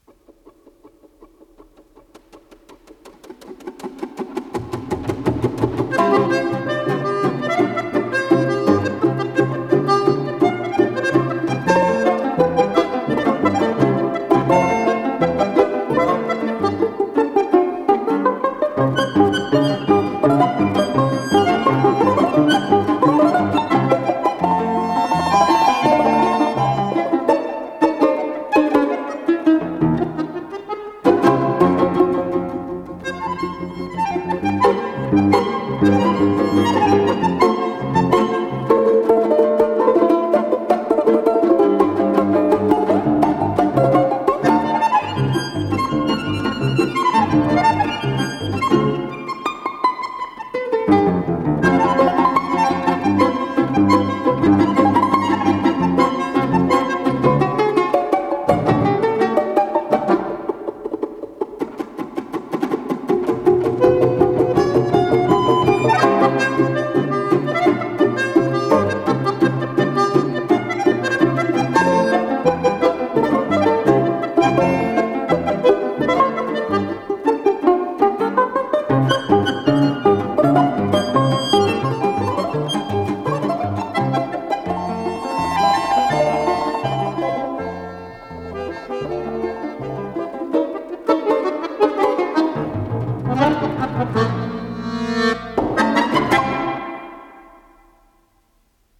с профессиональной магнитной ленты
домра
баян
балалайка
балалайка-контрабас
ВариантДубль моно